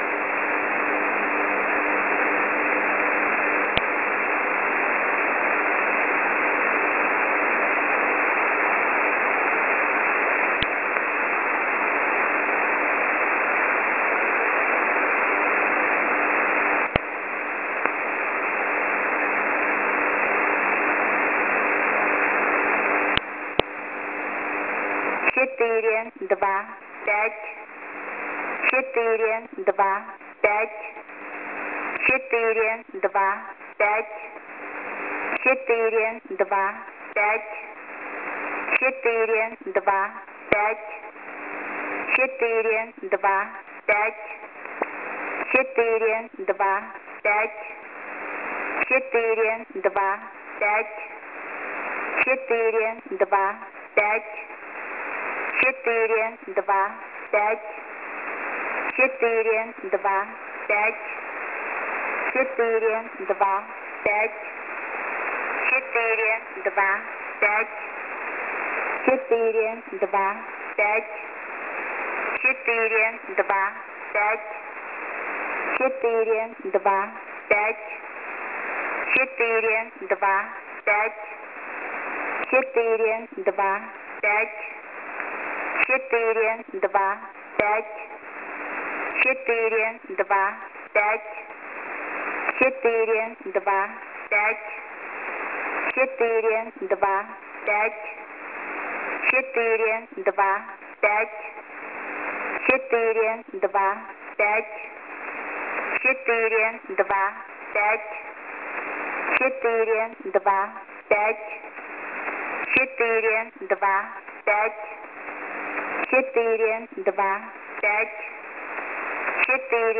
Mode: USB + Carrier